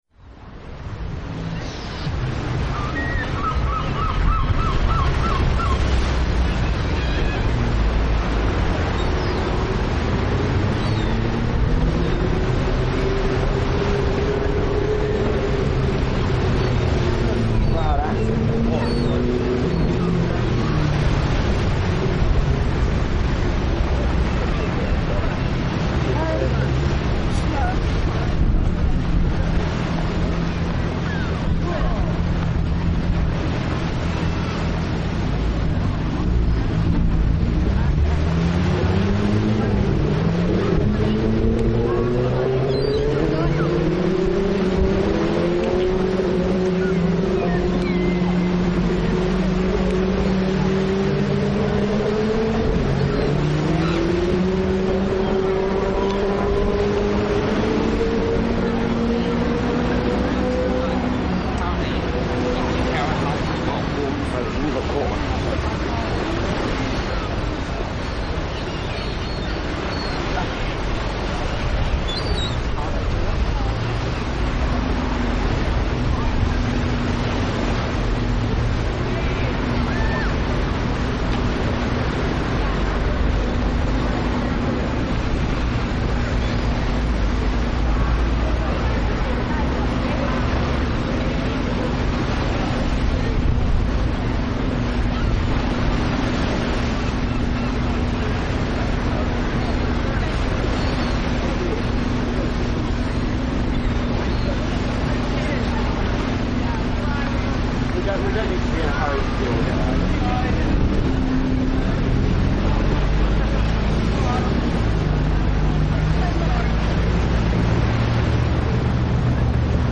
It doesn't contain any orchestration.
There's a fair amount of wind and some motor bikes which were quite a long way away as I remember it. People were wandering past, sometimes talking to each other, which wasn't intentional, it just happened.
There is some maniacal laughter on the left speaker, and later some seagulls. There are a lot of vehicles on the right speaker, which must mean I was recording it near a road. Towards the end two or three children turn up, one calling for its mother.
The track fades out shortly after this.
Waves gulls and people I 64k.mp3